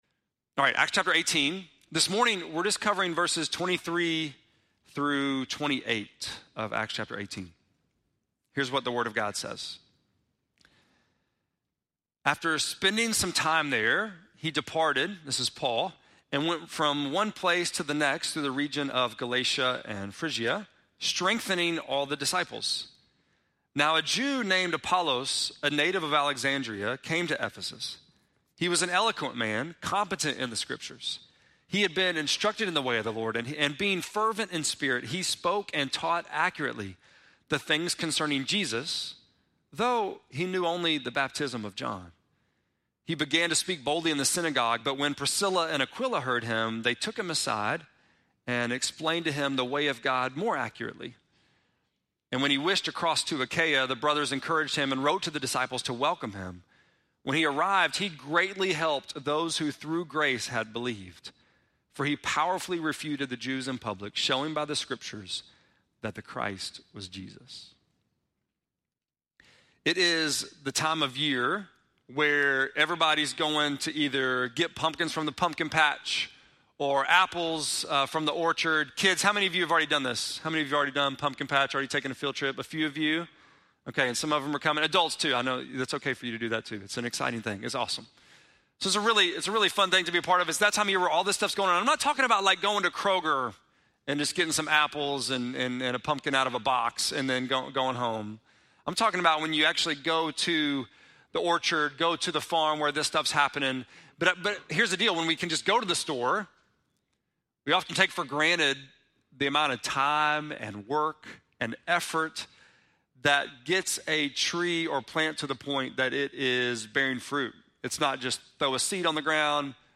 10.6-sermon.mp3